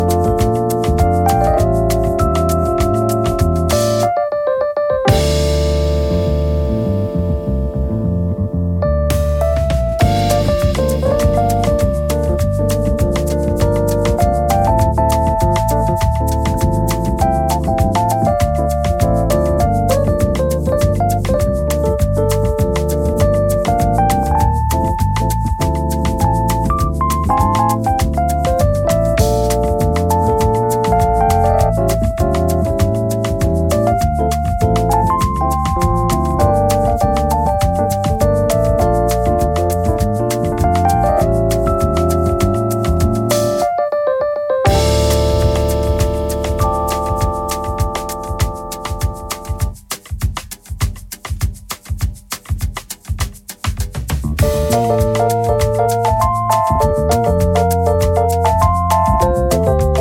ジャンル(スタイル) JAZZ / POP JAZZ